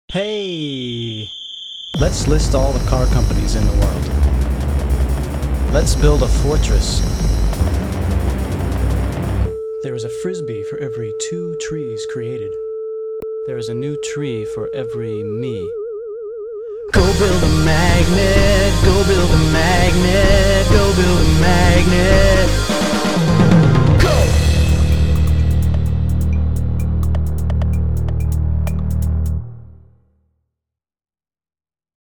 BPM32-140